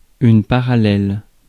Ääntäminen
IPA : /ˈpæɹəlɛl/